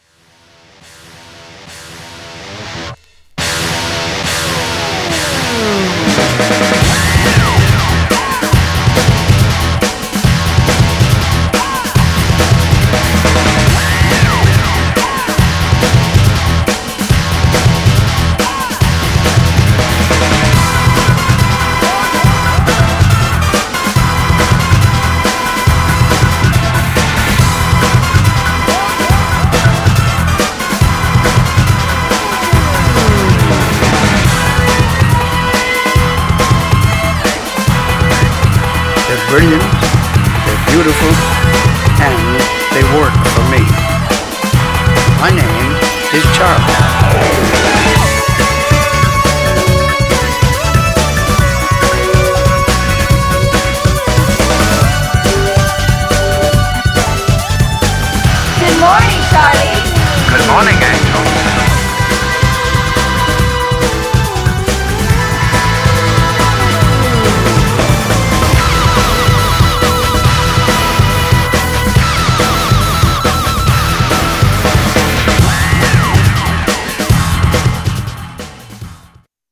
Styl: Drum'n'bass, Trance